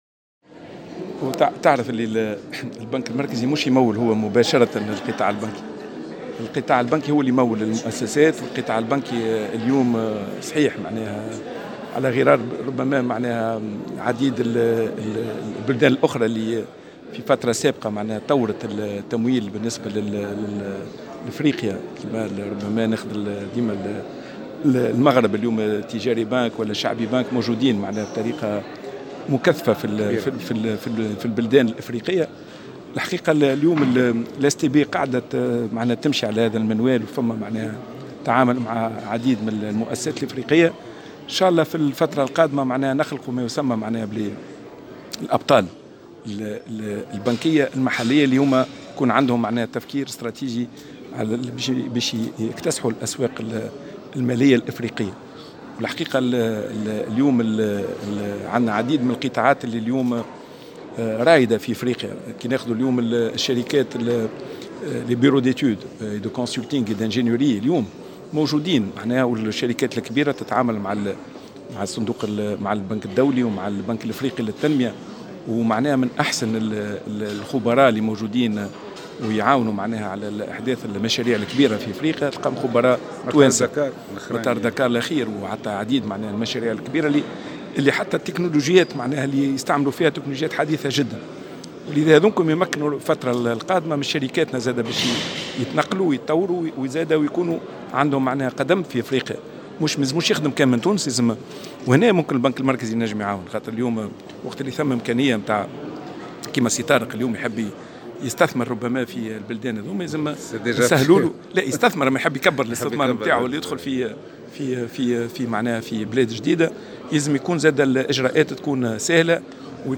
كما أشار في تصريحه على هامش ملتقى اقتصادي لمنظمة "كونكت" بتونس العاصمة، إلى تأثير محتمل لهذه الأزمة على شركات الاستيراد في تونس والتي تقوم بتوريد المواد الأولية من الصين بوصفها الشريك التجاري الأول لتونس على مستوى التوريد.